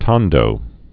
(tŏndō, tôn-)